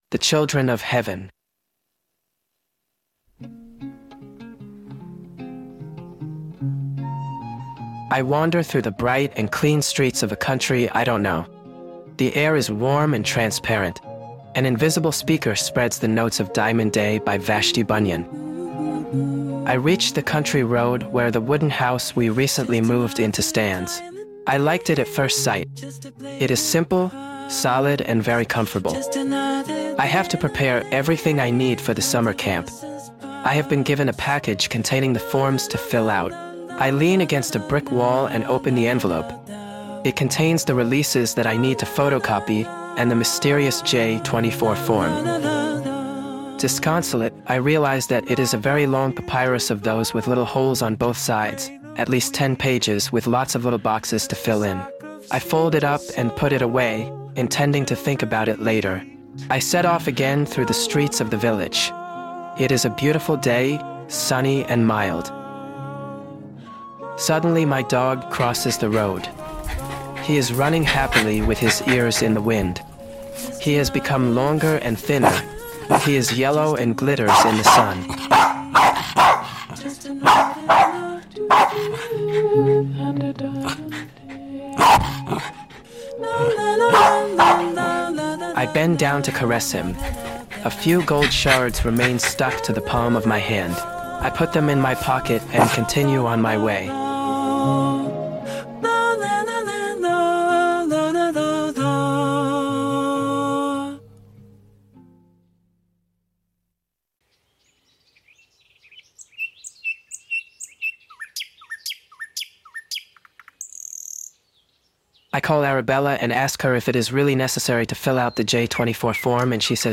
The interpreter is Paul Emmanuel (AI).
The soundtrack consists of covers or instrumental versions of "Just Another Diamond Day" by Vashti Bunyan.